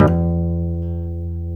F#2 HAMRNYL.wav